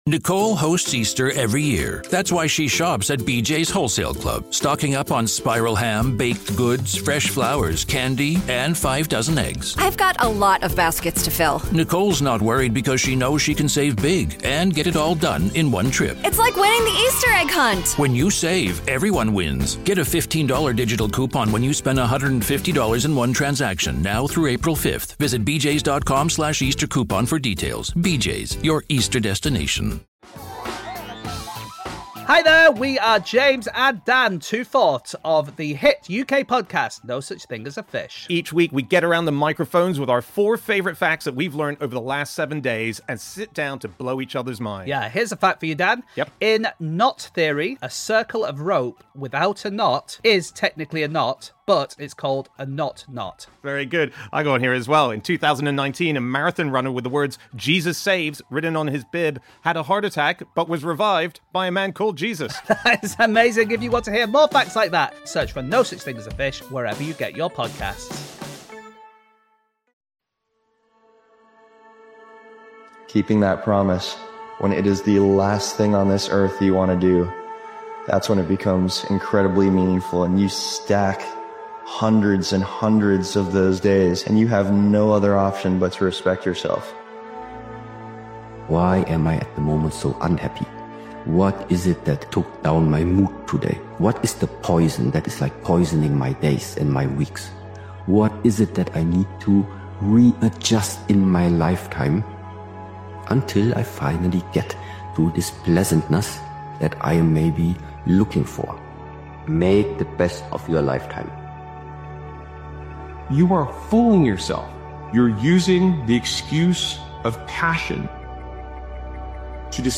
Powerful Motivational Speech Video is a raw and urgent motivational video created and edited by Daily Motivations. This powerful motivational speeches compilation speaks to the moment where quitting feels tempting—but walking away would cost you everything.